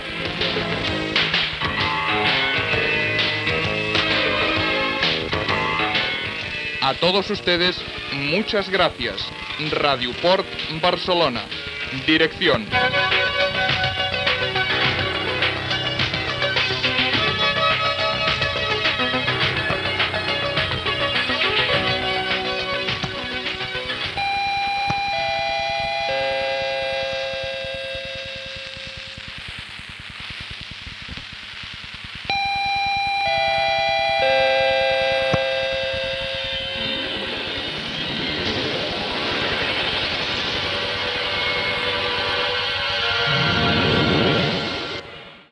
Identificació de l'emissora